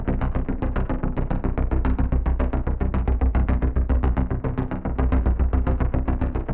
Index of /musicradar/dystopian-drone-samples/Droney Arps/110bpm
DD_DroneyArp3_110-C.wav